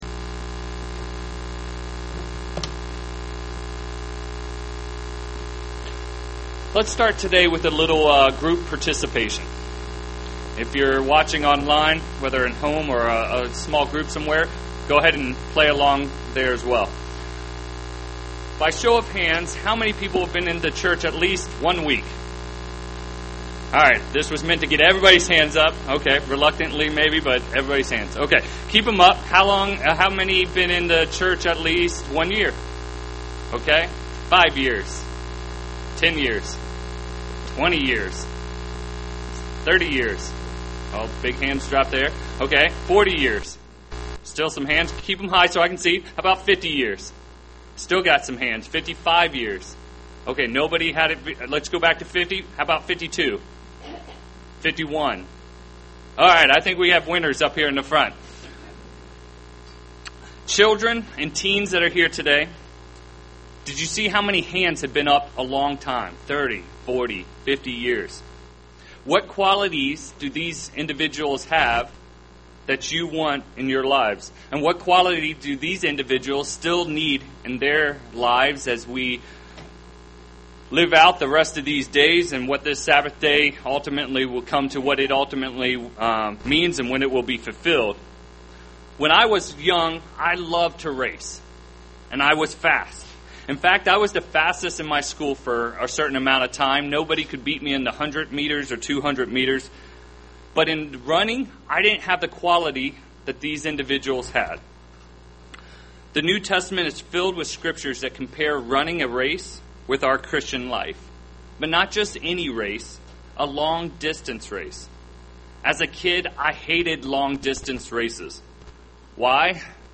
Sermon
Given in Cincinnati East, OH